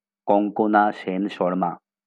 Konkona Sen Sharma (Bengali: [kɔŋkɔna ʃen ʃɔrma]